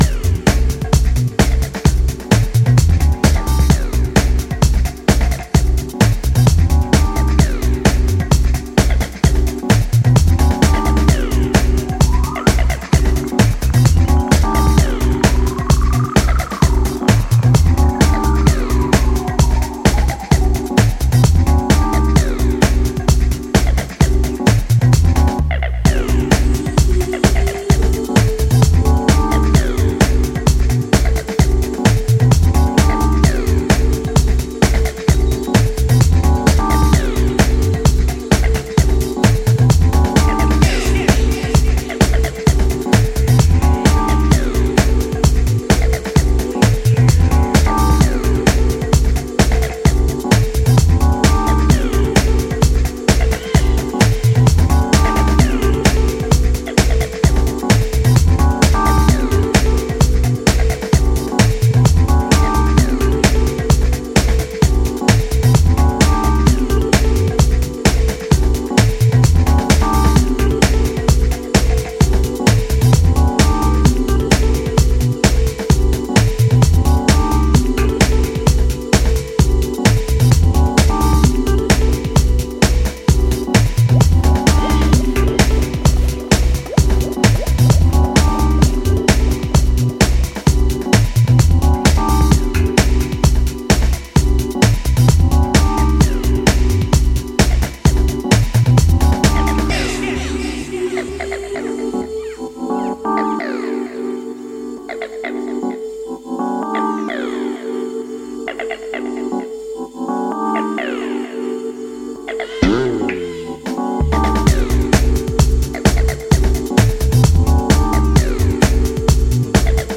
a perky house/disco beaming like the sun does on a hot day
trip hoppy groove into hypnotic, loopy, spaces